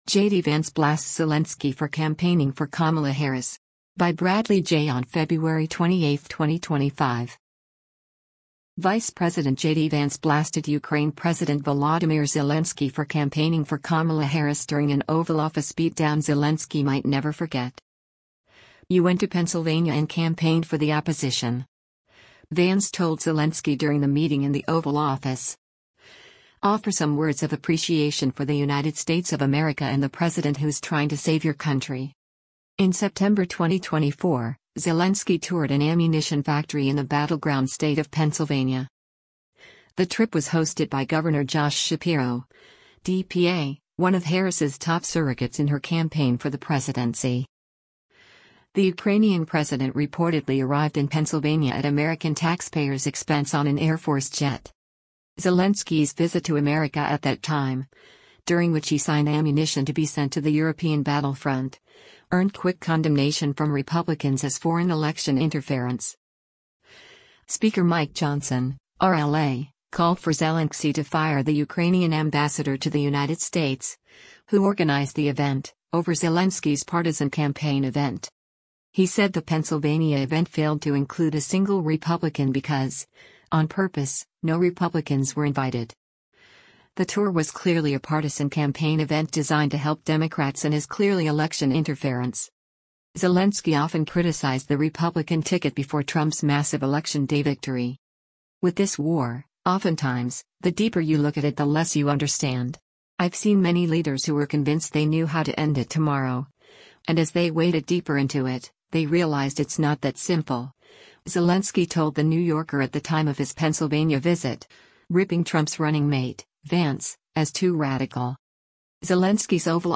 Vice President JD Vance blasted Ukraine President Volodymyr Zelensky for campaigning for Kamala Harris during an Oval Office beatdown Zelensky might never forget.